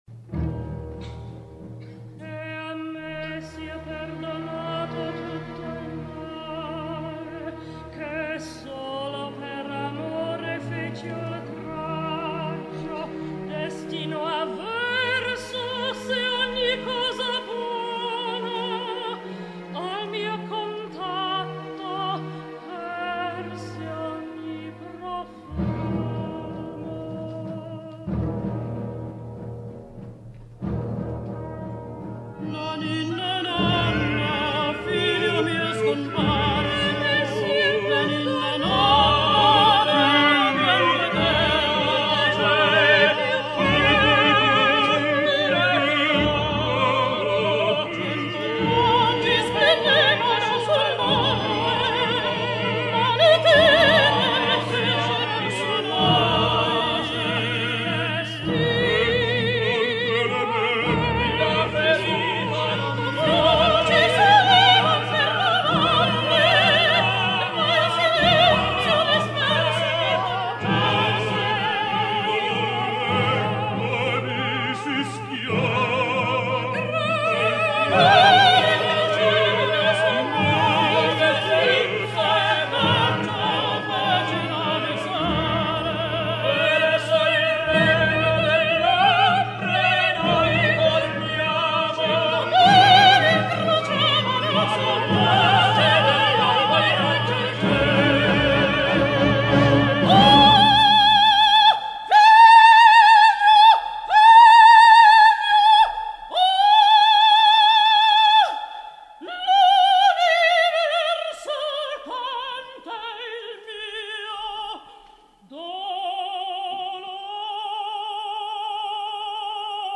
dramma musicale in tre atti
Auditorium del Foro Italico, 24 settembre 1960)